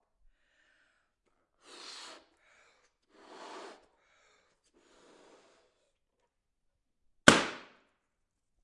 吹气球
描述：气球弹出：快速爆裂，爆炸，泡沫破裂，响亮的流行音乐。使用ZOOMH4n在声音室中录制。
标签： 大声 爆炸 爆炸 轰的一声 爆炸 流行音乐 气球 泡沫 破灭 OWI 炸弹
声道立体声